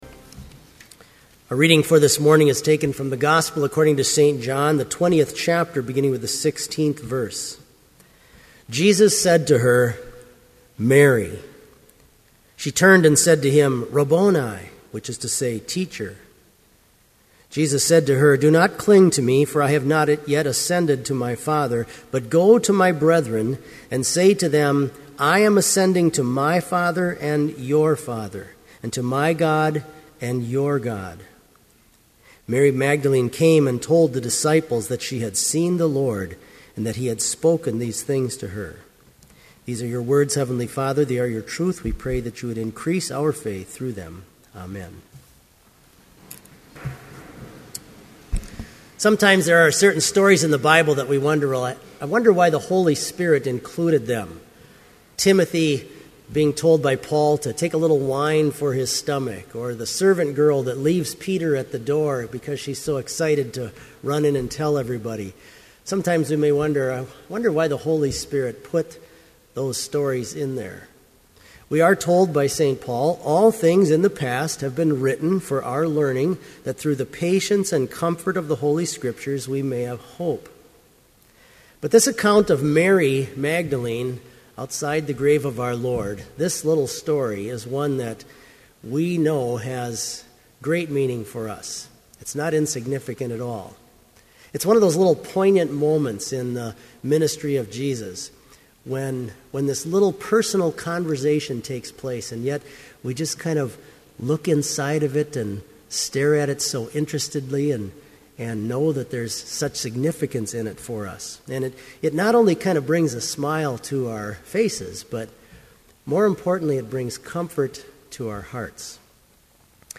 Complete service audio for Chapel - April 13, 2012